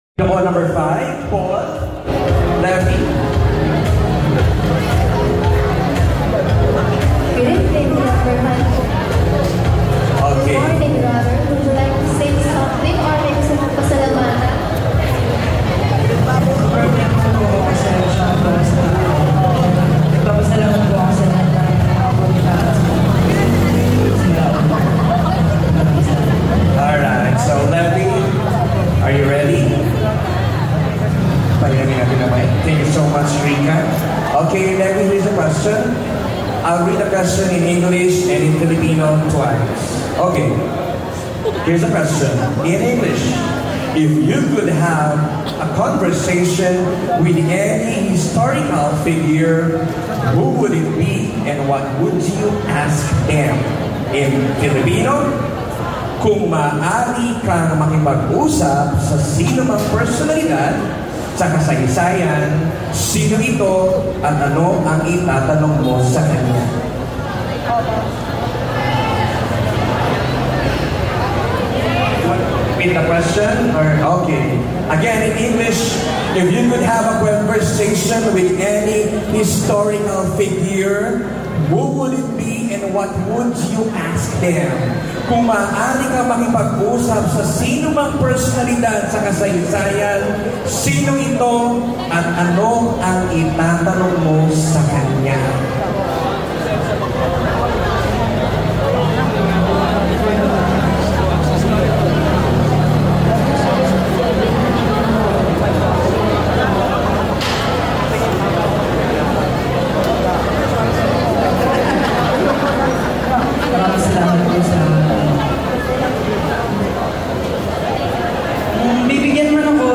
TOP 5 FINALISTS Q AND A PORTION